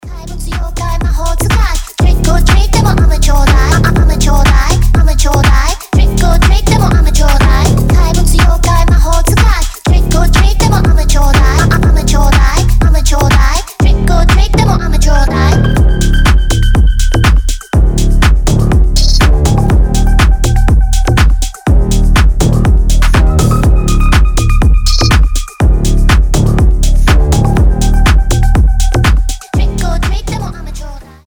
• Качество: 320, Stereo
мощные басы
качающие
slap house
Анимешный голос и суровый дроп